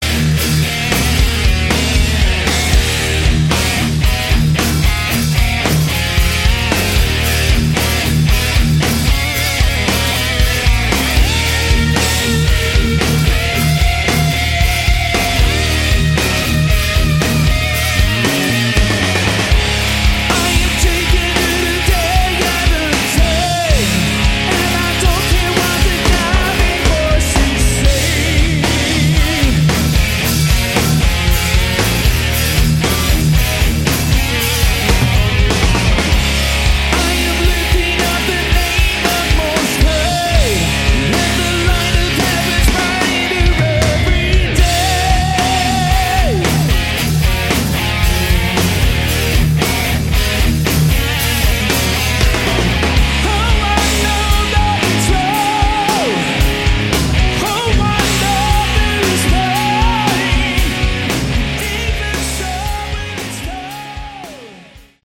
Category: Hard Rock
lead vocals, guitar
vocals, guitars
bass, vocals
drums